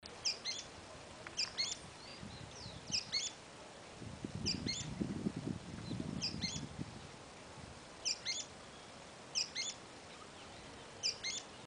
Sooty-fronted Spinetail (Synallaxis frontalis)
Life Stage: Adult
Location or protected area: Reserva Natural del Pilar
Condition: Wild
Certainty: Recorded vocal